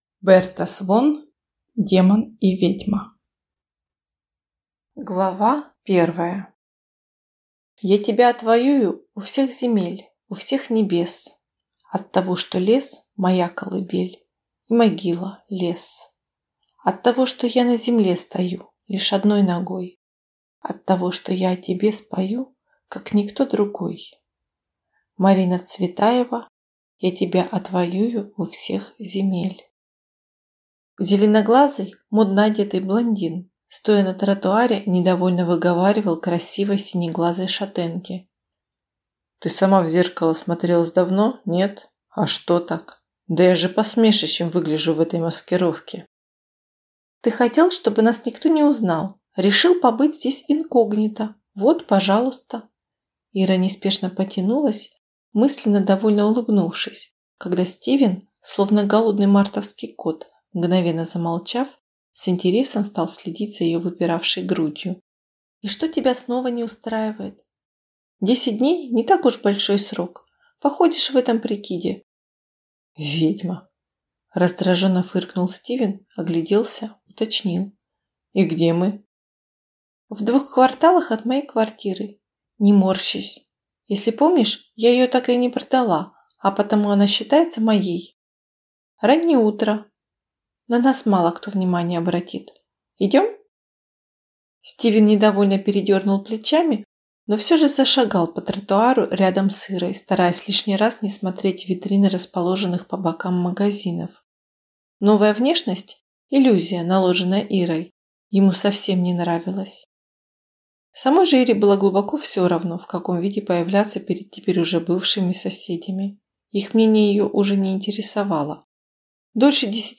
Аудиокнига Демон и ведьма | Библиотека аудиокниг